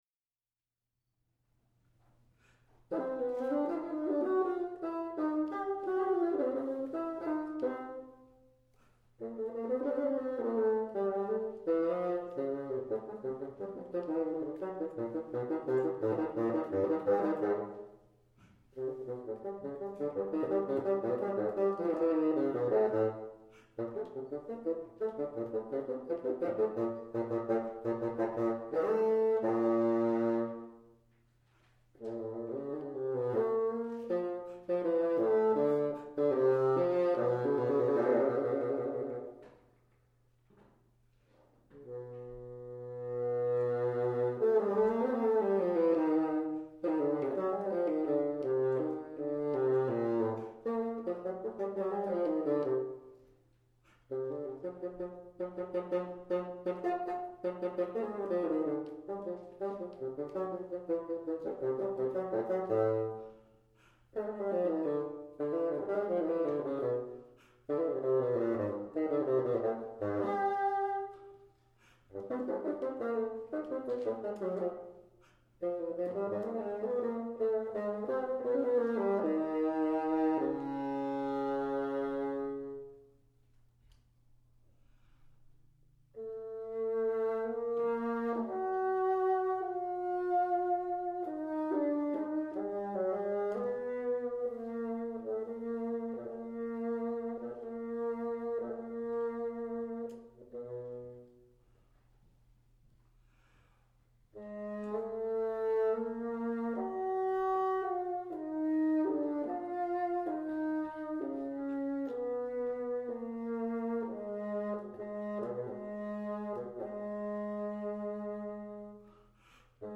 Portland Community Music Center
Bassoon
are a couple of jazz devices (licks) in this piece.